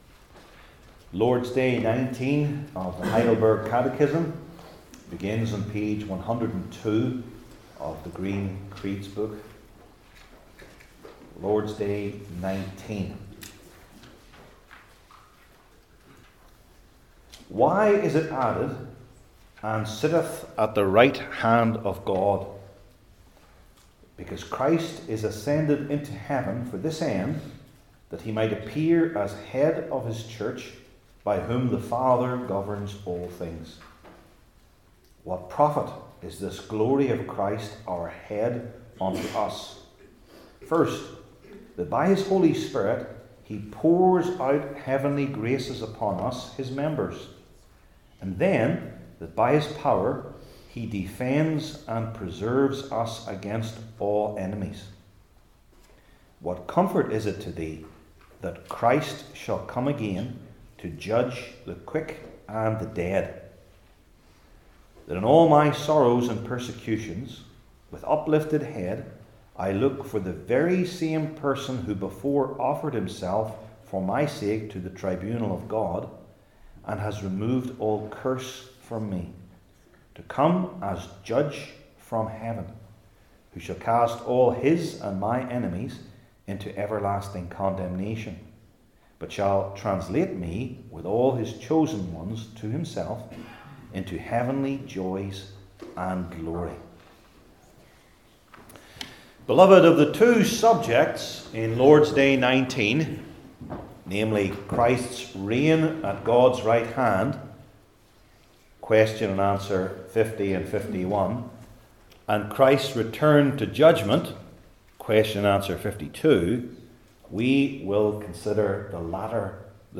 Heidelberg Catechism Sermons